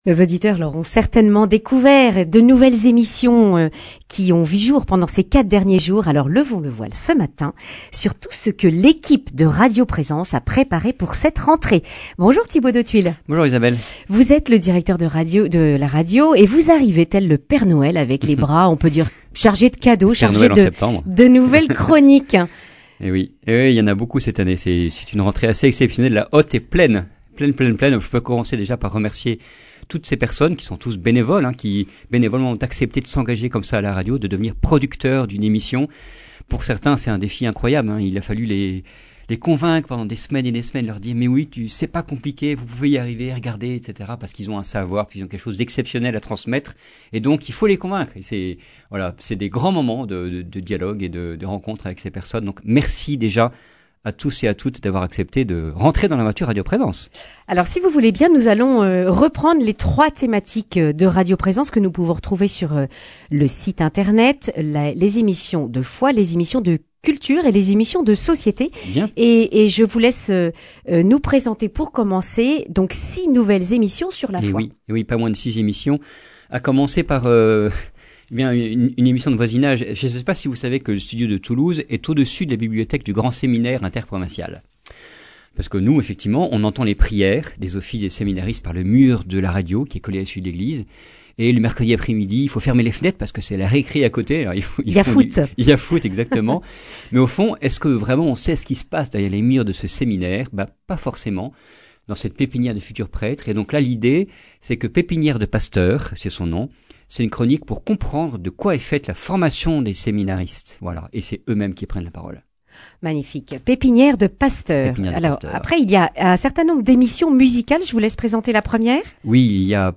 Accueil \ Emissions \ Information \ Régionale \ Le grand entretien \ Des nouvelles émissions sur radio Présence !